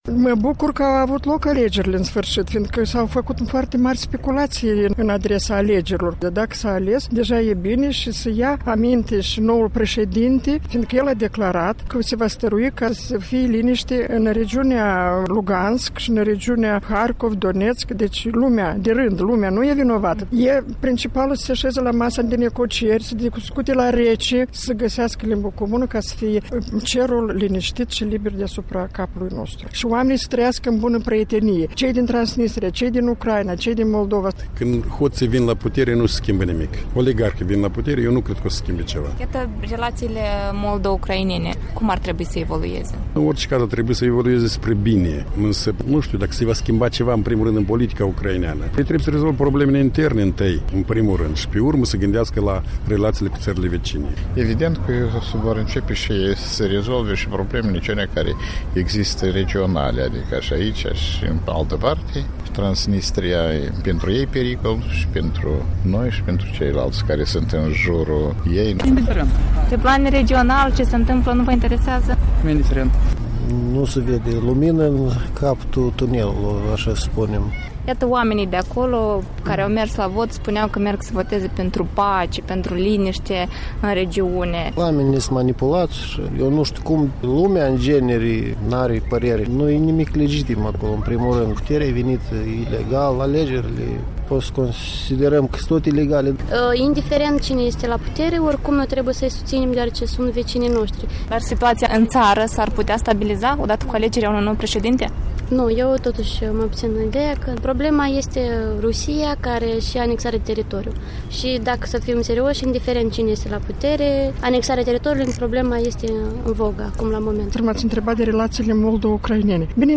Vox populi: rezultatele alegerilor din Ucraina